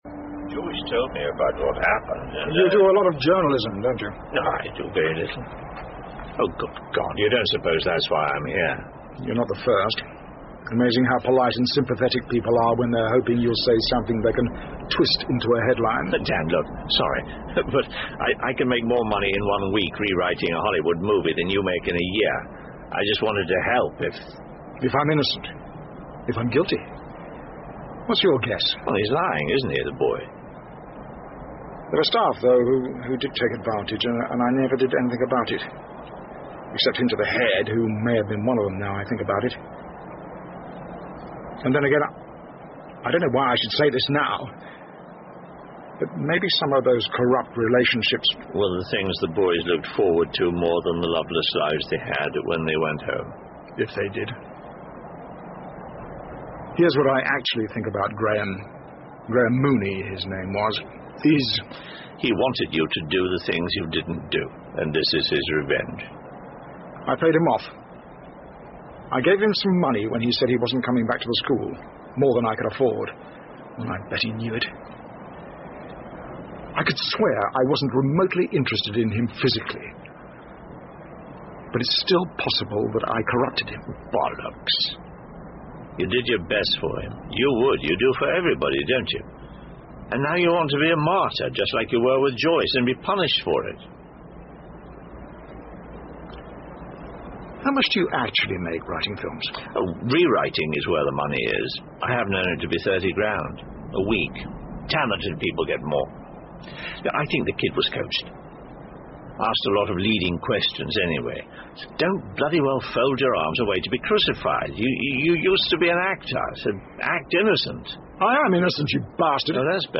英文广播剧在线听 Fame and Fortune - 20 听力文件下载—在线英语听力室